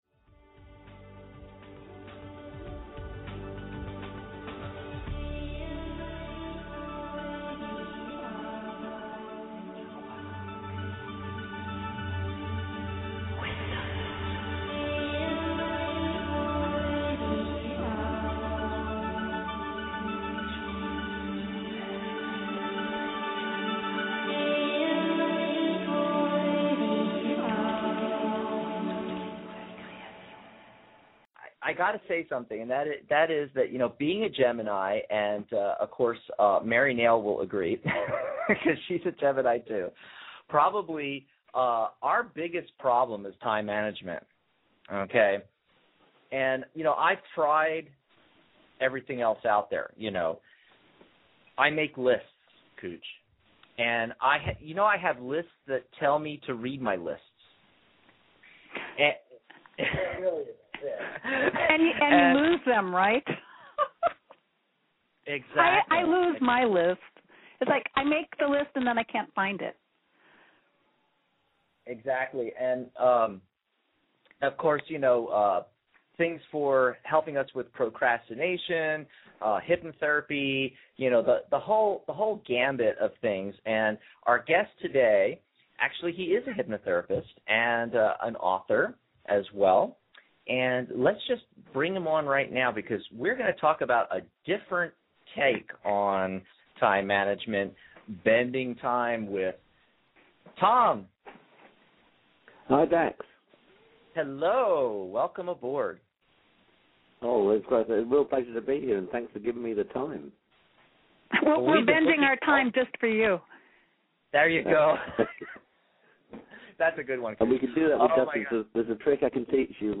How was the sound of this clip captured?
sound a little muffled but persevere for some gems of wisdom